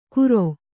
♪ kuro